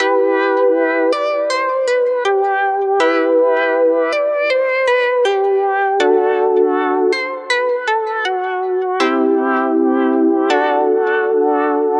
回声
描述：丘陵，环境的合成器循环
标签： 80 bpm Chill Out Loops Synth Loops 1.01 MB wav Key : G
声道单声道